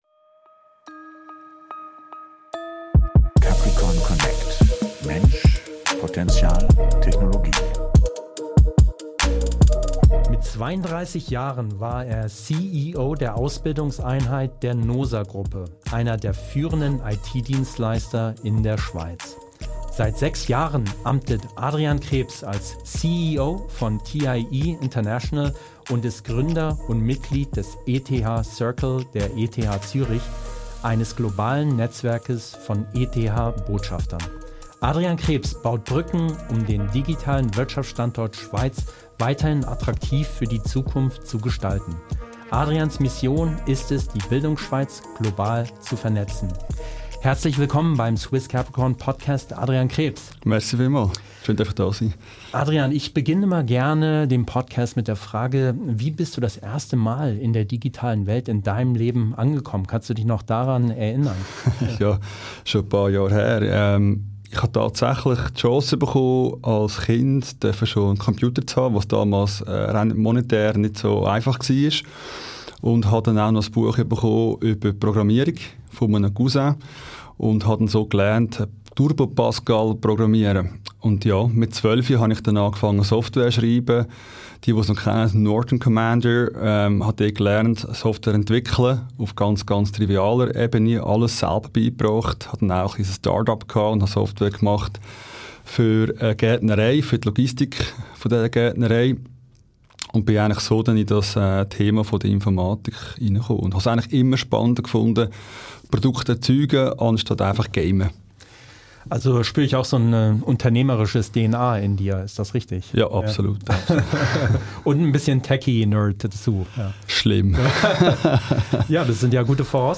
#52 - Interview